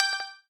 Beep on Replay Buffer Save plugin
(it’s loud asf by default)
Beep.wav